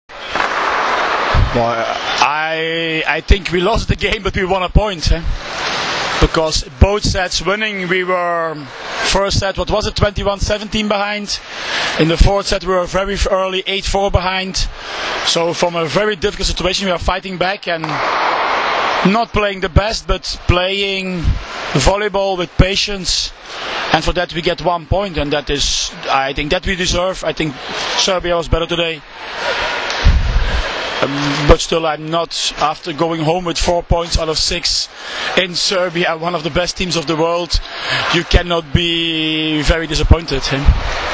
IZJAVA VITALA HEJNENA